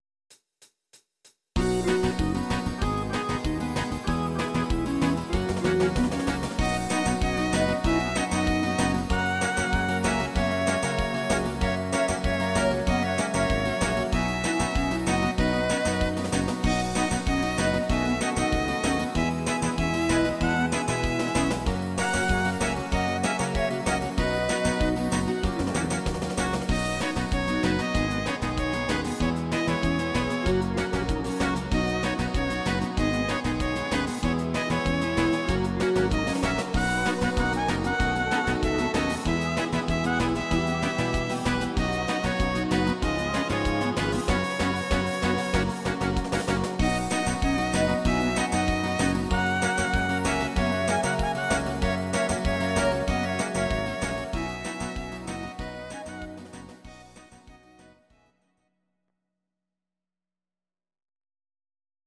Audio Recordings based on Midi-files
German, 1960s